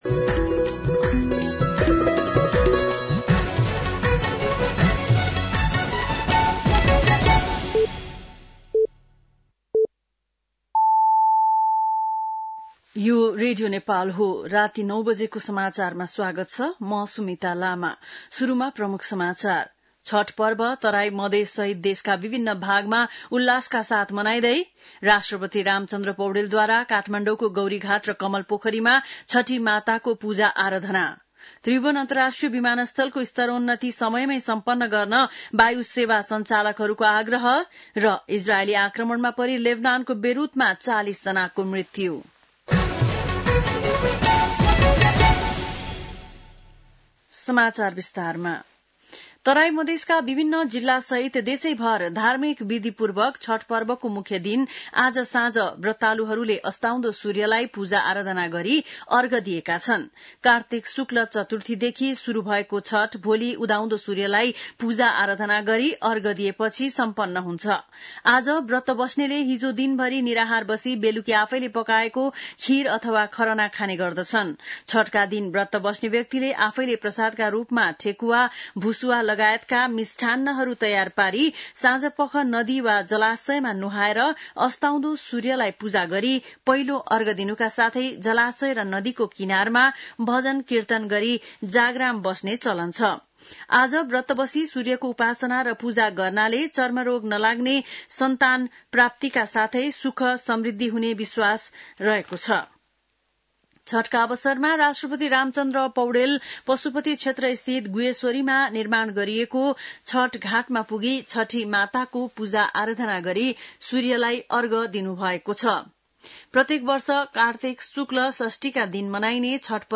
बेलुकी ९ बजेको नेपाली समाचार : २३ कार्तिक , २०८१